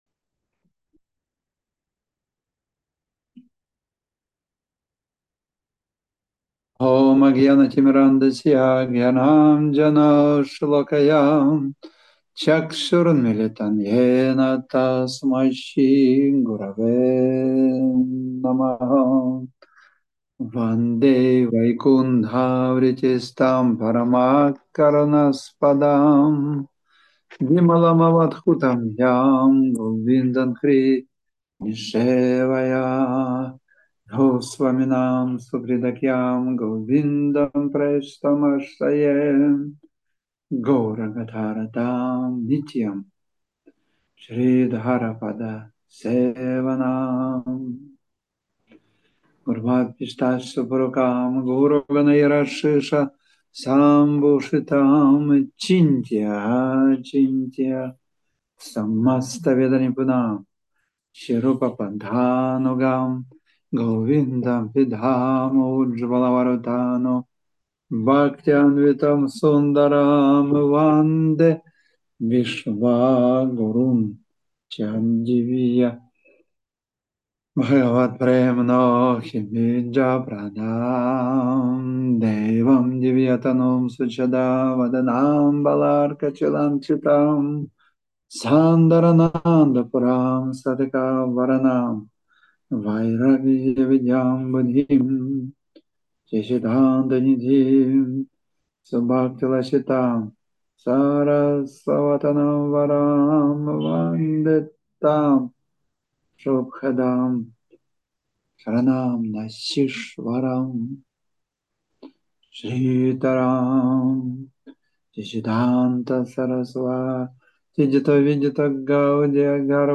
Место: Тбилиси (Грузия)
Лекции полностью